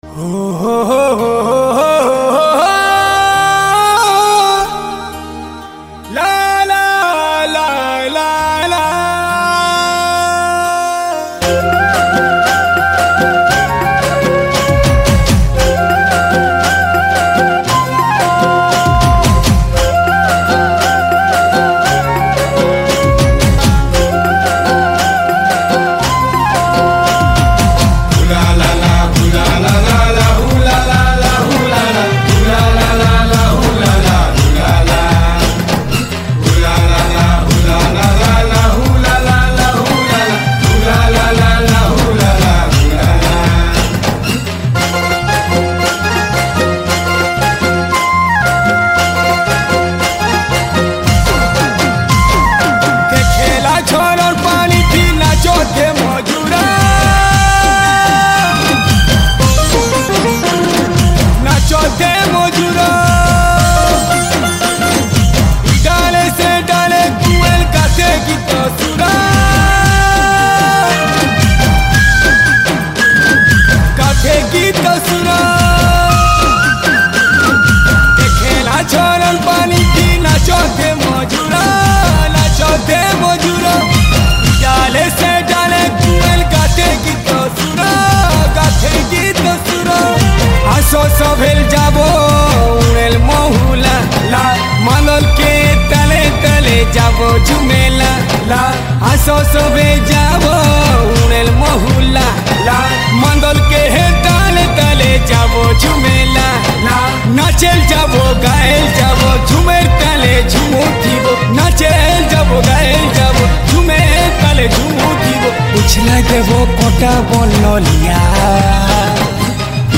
Sambalpuri Folk Song